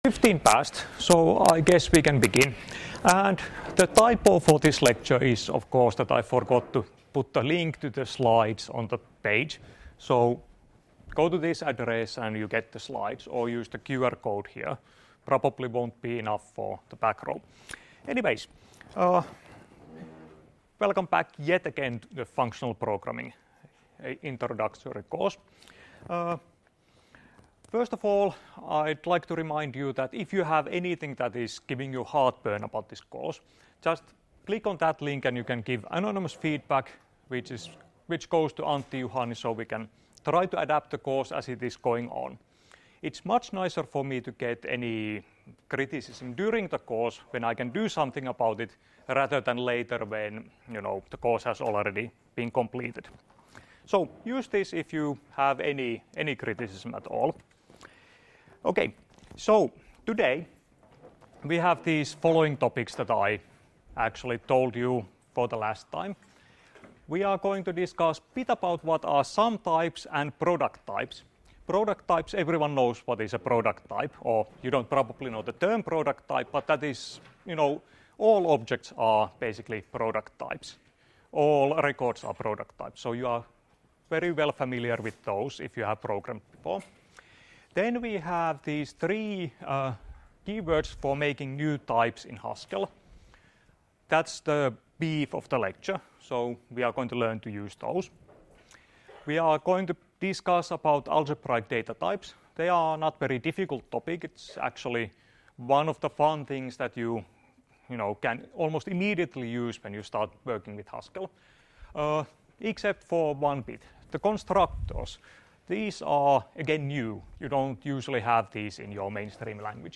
Luento 30.1.2017 — Moniviestin